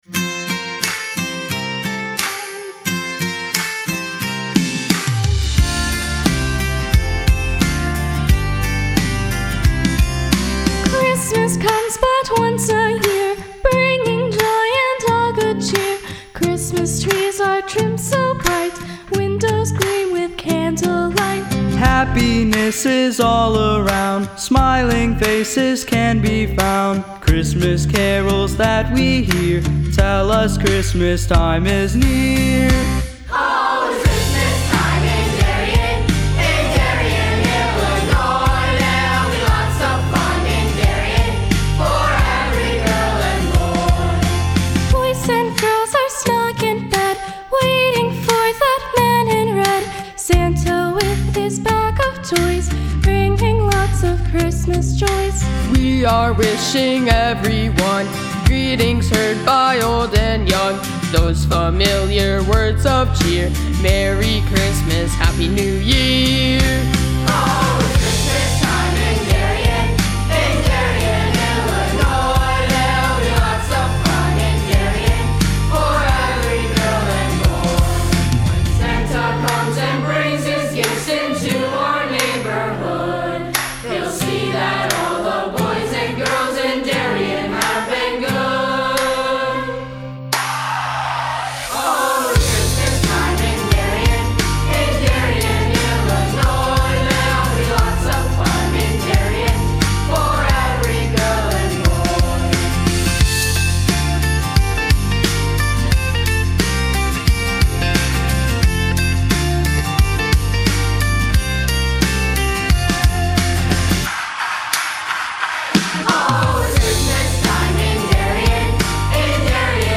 Our Choir Members are Officially Recording Artists!
christmastime-in-darien-lakeview-junior-high-school-choir.mp3